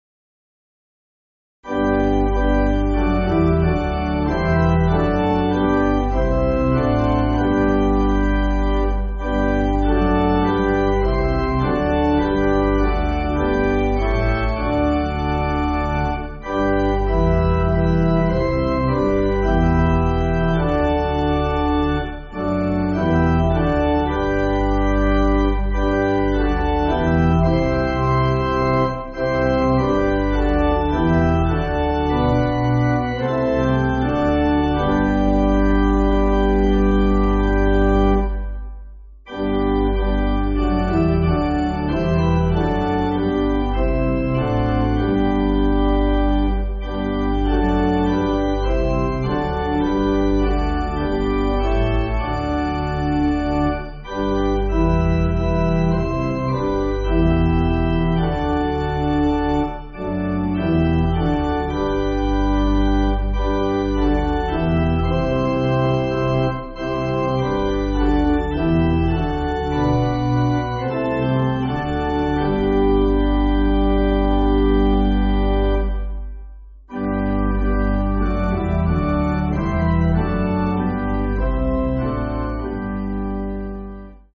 (CM)   4/G